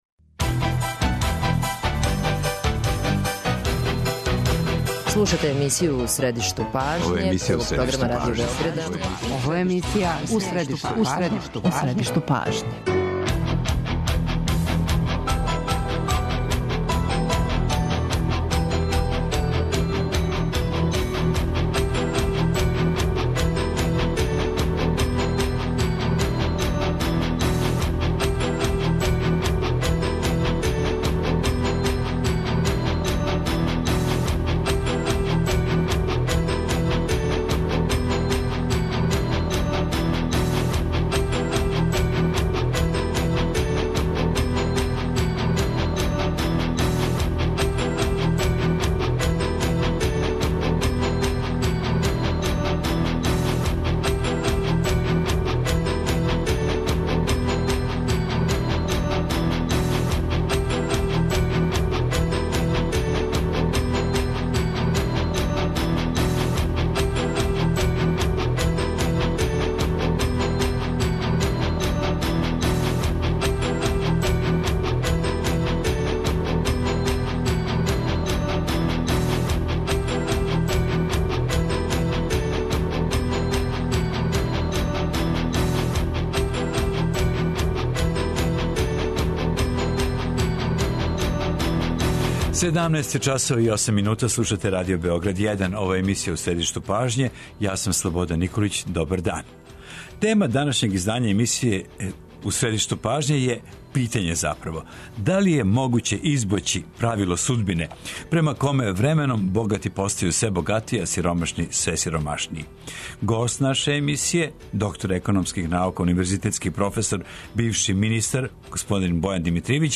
Гост емисије У средишту пажње, економиста, професор на Educons универзитету и бивши министар трговине и туризма, Бојан Димитријевић, верује да је то могуће, уколико се Србија определи за корениту и свеобухватну промену економске филозофије и политике.
преузми : 25.68 MB У средишту пажње Autor: Редакција магазинског програма Свакога радног дана емисија "У средишту пажње" доноси интервју са нашим најбољим аналитичарима и коментаторима, политичарима и експертима, друштвеним иноваторима и другим познатим личностима, или личностима које ће убрзо постати познате.